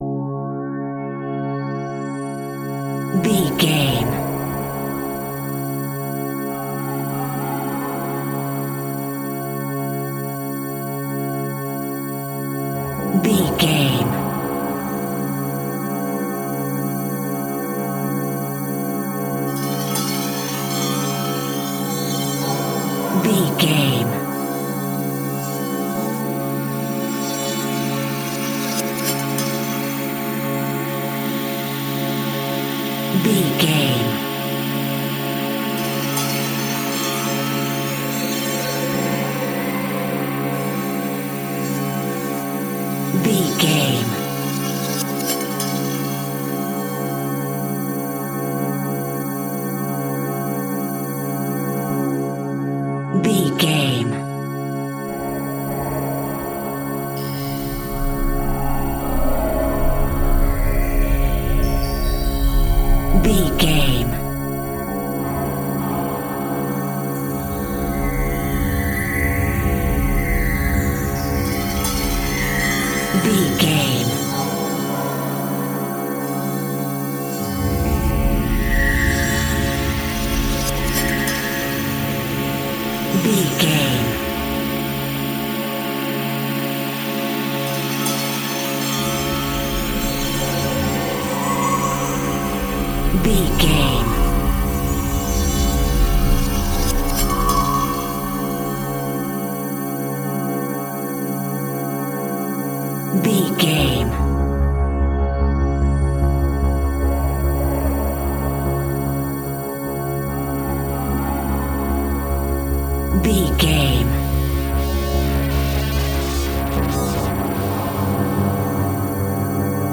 Horror Film Atmosphere Sounds.
In-crescendo
Thriller
Aeolian/Minor
C#
Slow
ominous
dark
eerie
synthesiser
horror music
Horror Pads
horror piano
Horror Synths